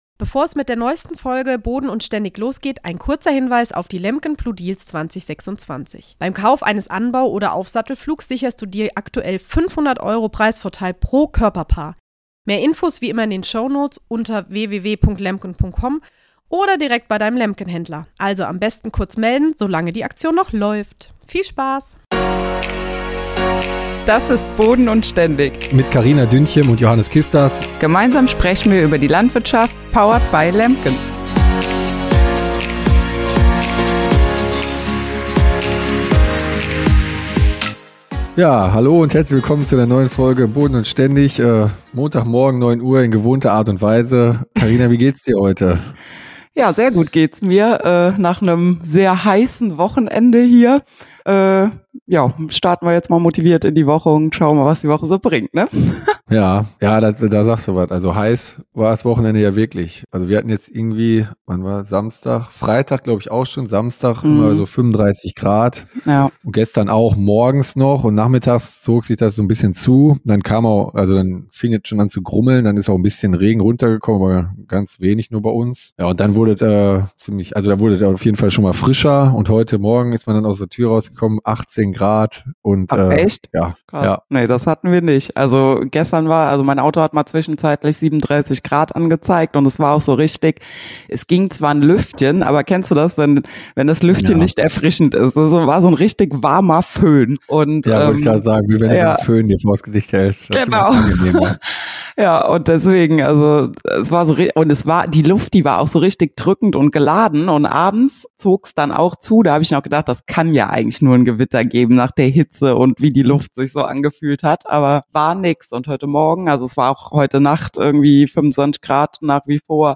Außerdem diskutieren die Hosts und ihr Gast, warum sich das betriebswirtschaftliche Know-how in der Landwirtschaft immer mehr lohnt – und wo Digitalisierung an die Grenzen stößt.